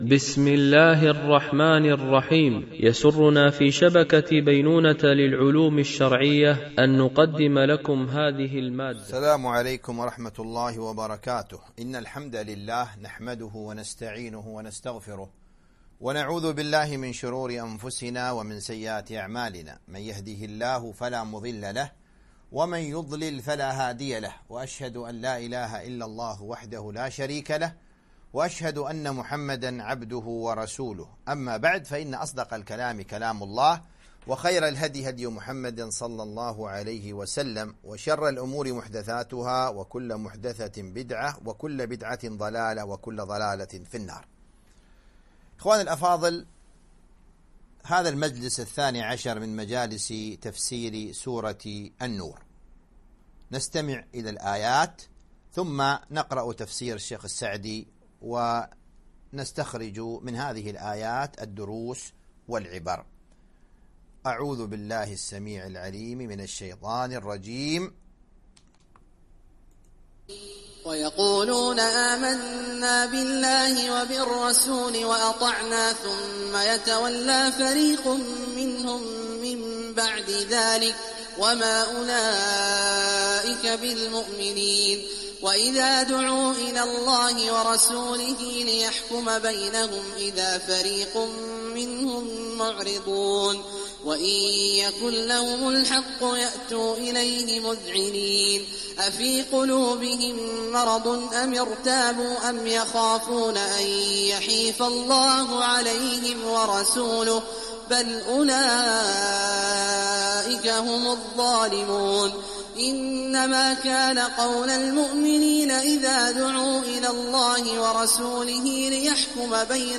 سلسلة محاضرات